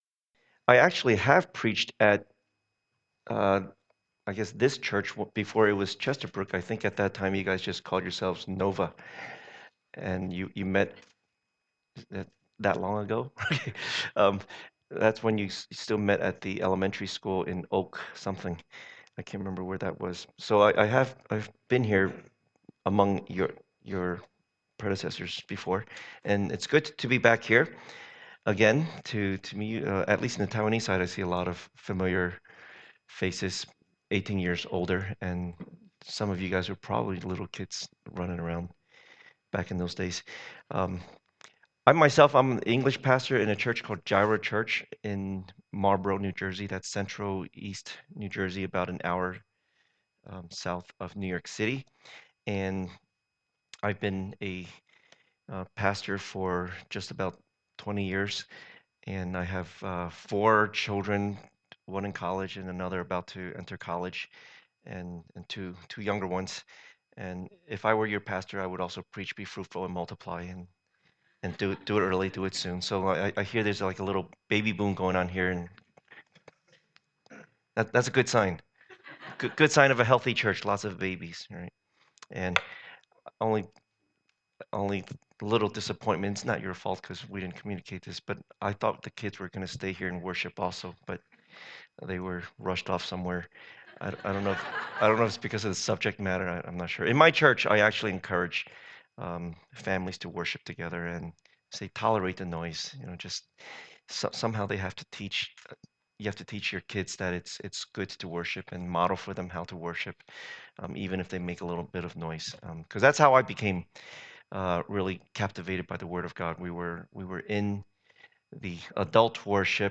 Passage: Hebrews 10:32-39 Service Type: Lord's Day